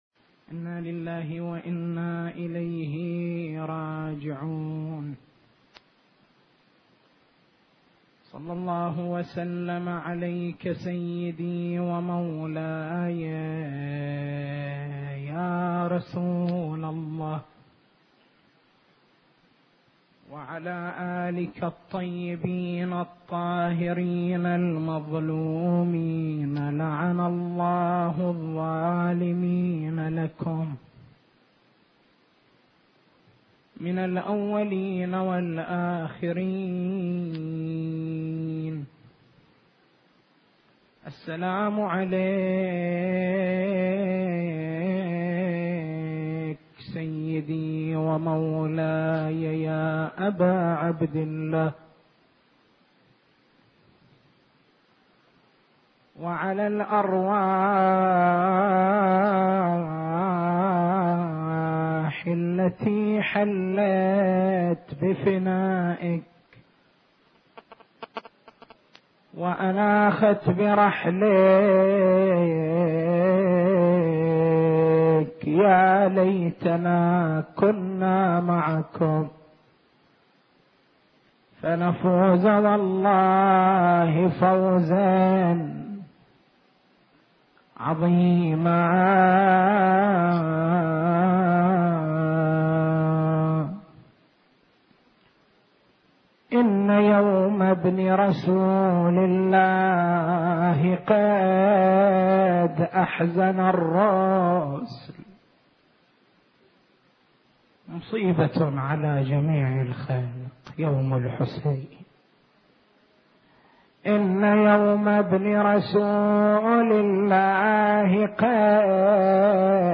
محاضرة
في الليلة الثانية من موسم محرم الحرام عام 1430هـ.